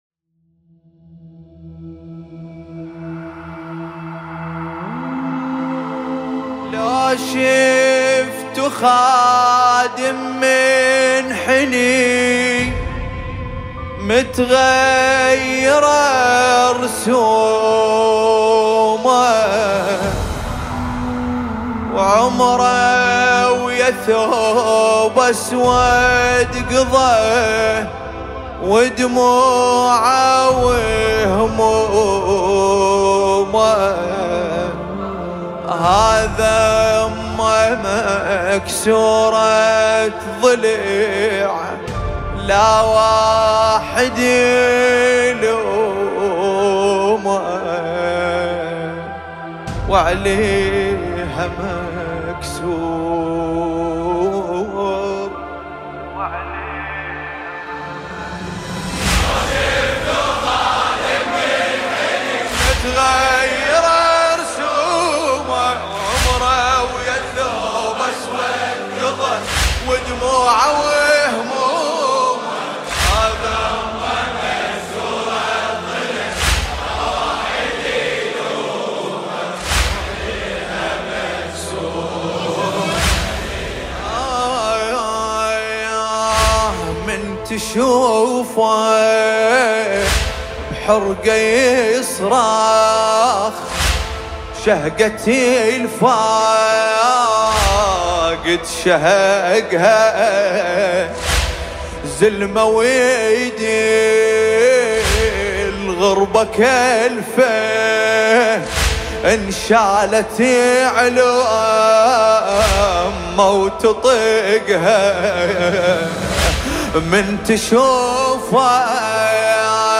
مداحی عربی دلنشین